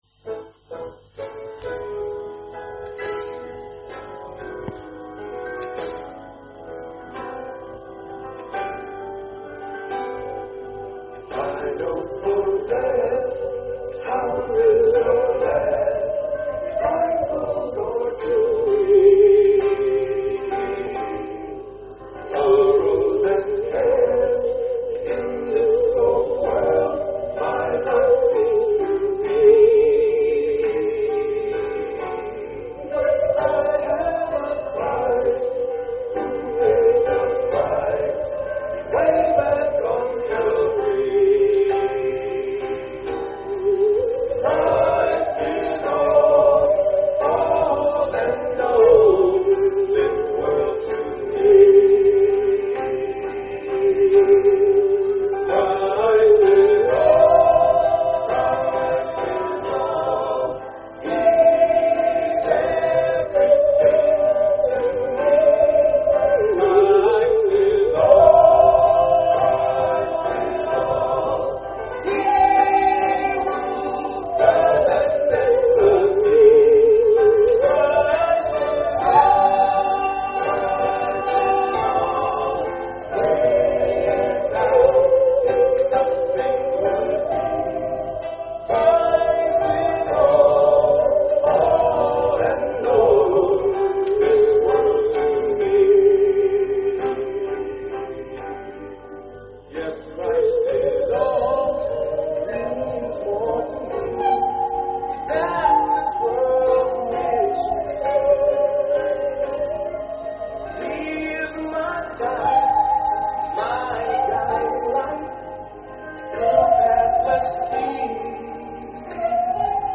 Special performances
Playing the Saw